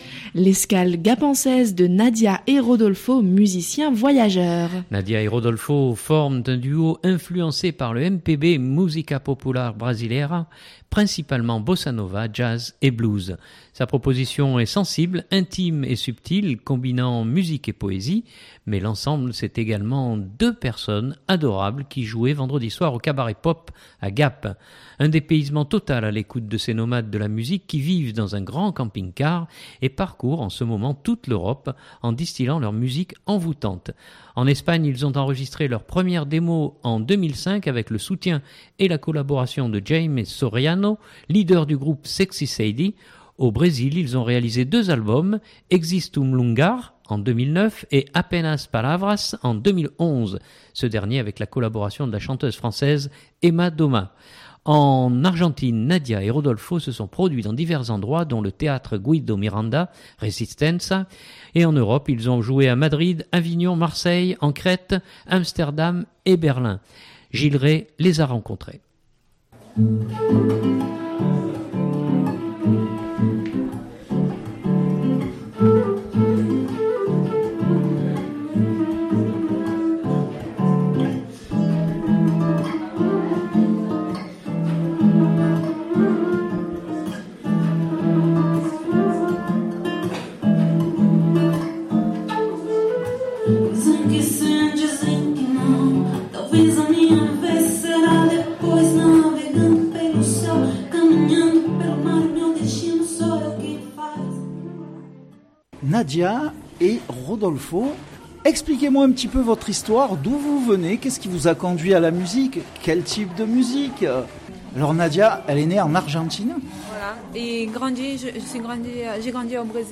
principalement Bossa Nova, Jazz et Blues. Sa proposition est sensible, intime et subtile combinant musique et poésie.